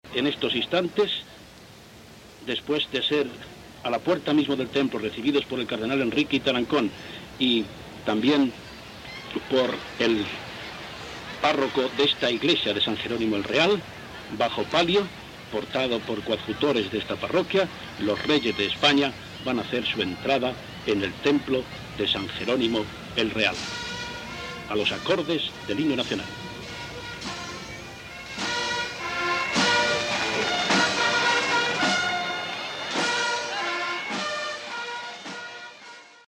Transmissió de la missa solemne als Jerónimos el Real de Madrid amb l'entrada dels reis espanyols sota pali.
Informatiu